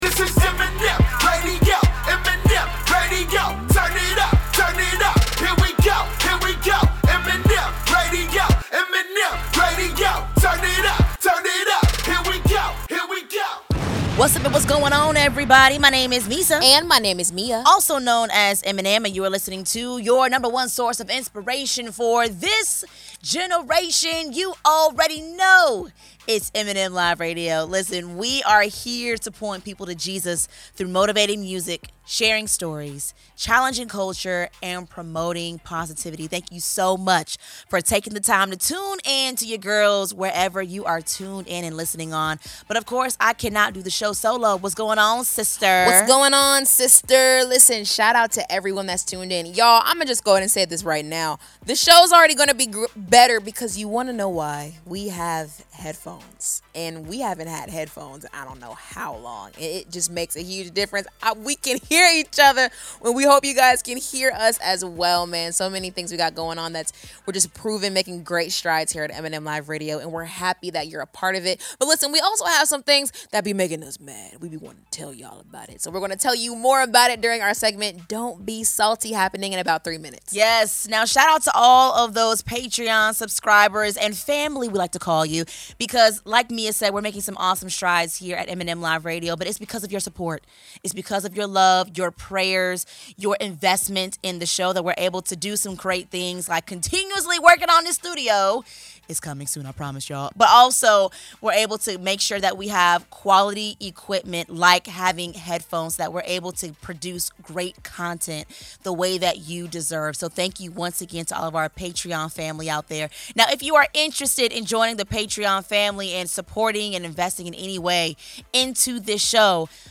From awkward moments to hard lessons and everything in between, the ladies share their personal stories, what they’ve learned along the way, and how their perspectives have grown through it all.